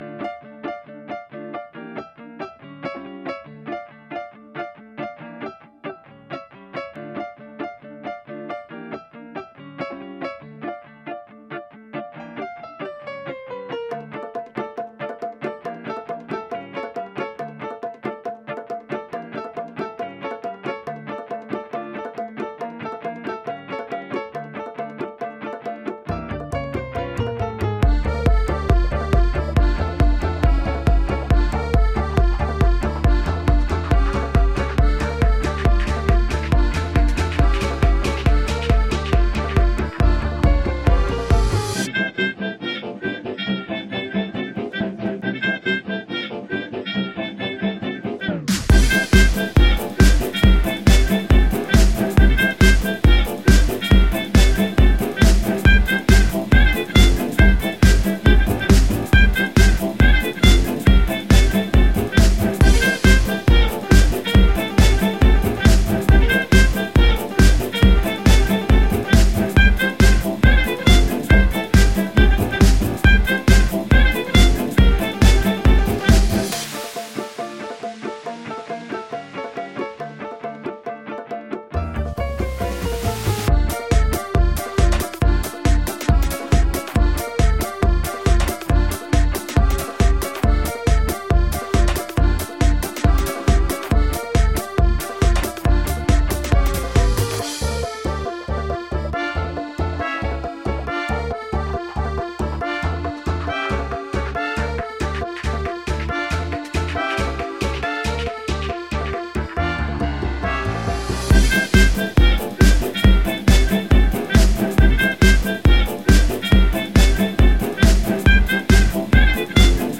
This album takes you on a Jazzy EDM Joyride.
Tagged as: Jazz, Instrumental Jazz, Experimental